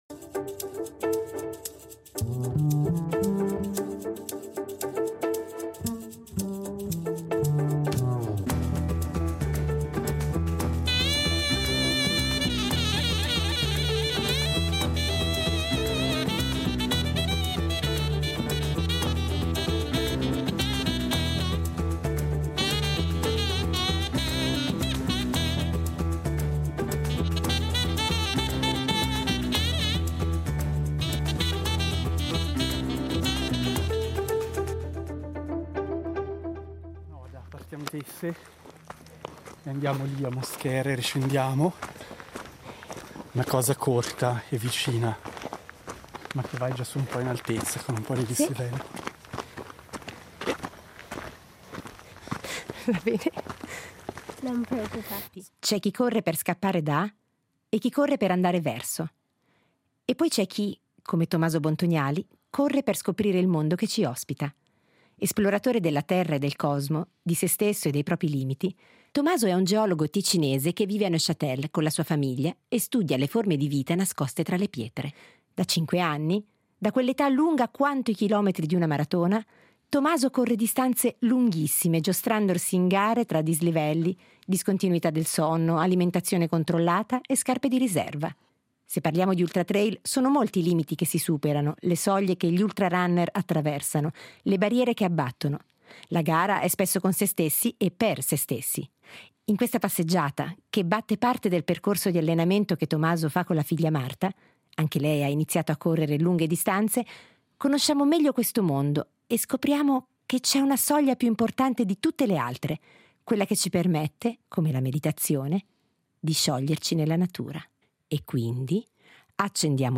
Lo abbiamo accompagnato lungo il tragitto di un suo allenamento tipico, al passo dell’intervistatrice, per farci raccontare questo mondo che sebbene sempre di corsa, è capace di uno sguardo profondo e di una comunione con la natura non indifferenti.